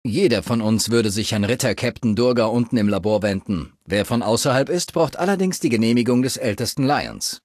Datei:Maleadult01default dialogueci citknightdirect 00026c46.ogg
in: Fallout 3: Audiodialoge Datei : Maleadult01default dialogueci citknightdirect 00026c46.ogg Quelltext anzeigen TimedText Versionsgeschichte Diskussion Version vom 10.